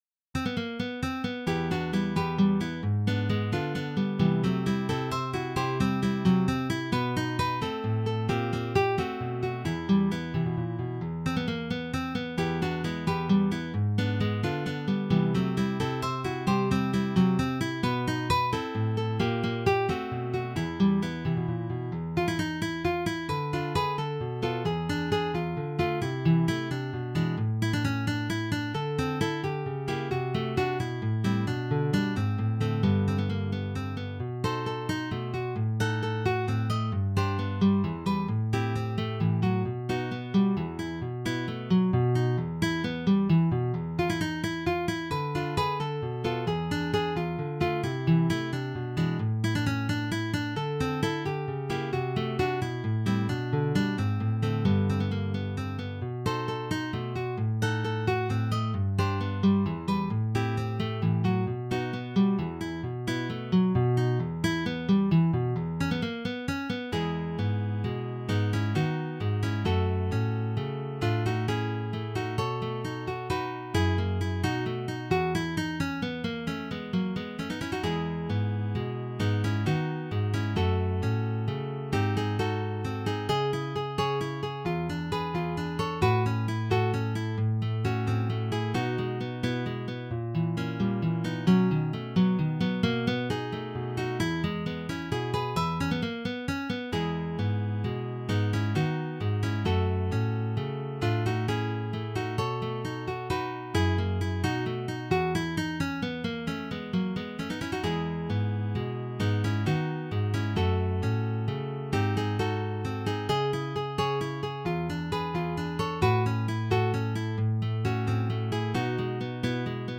arranged for four guitars